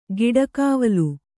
♪ giḍagāvalu